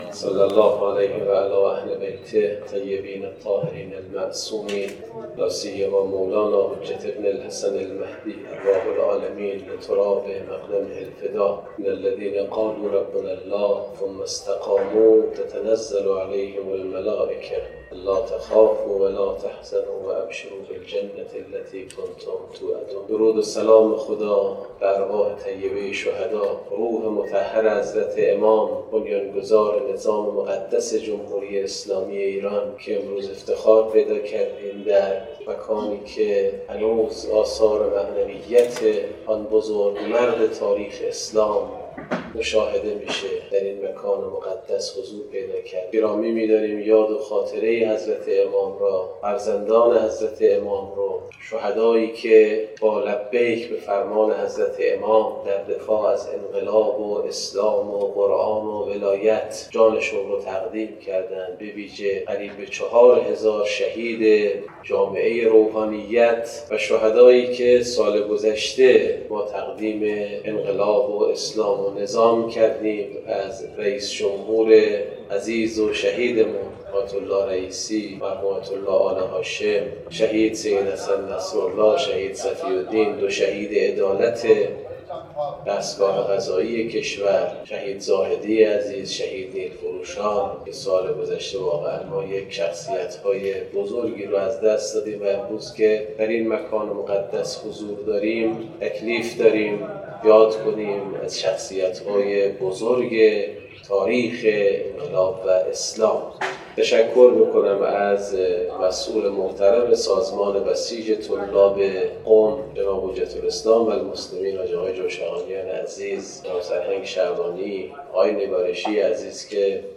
مراسم افتتاحیه دوره آموزشی تبیین منظومه فکری رهبر معظم انقلاب که در بیت تاریخی امام خمینی (ره) در قم